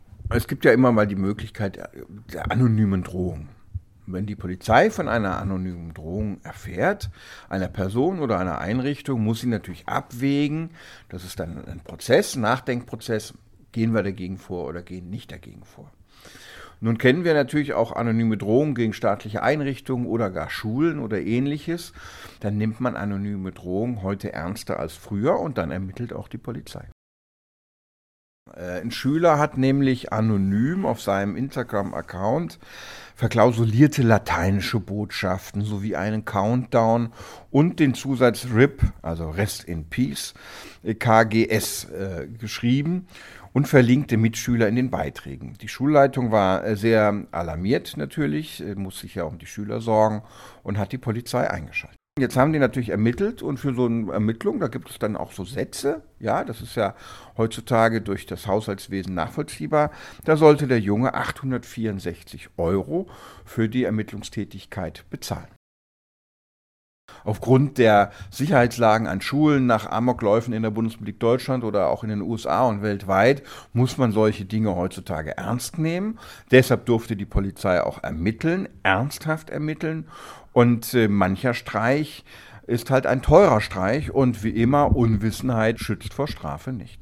Kollegengespräch: Anonyme Drohung bei Instagram – Schüler muss Polizeikosten tragen